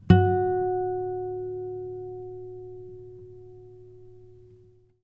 harmonic-02.wav